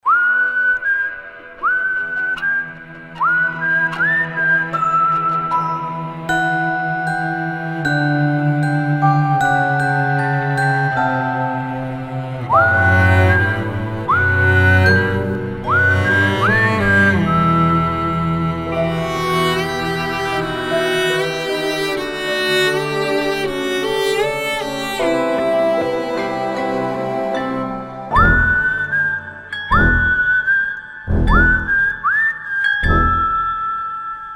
• Качество: 192, Stereo
свист
Завораживающие
bollywood
индийские